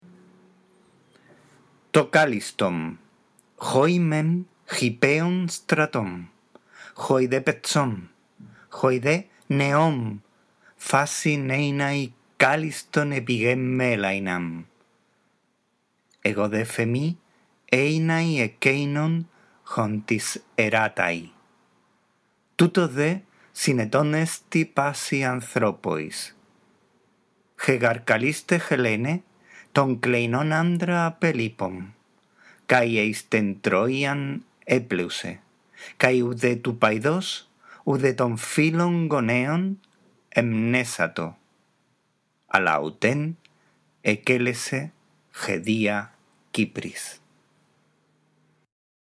La audición de este archivo te guiará en la lectura del texto griego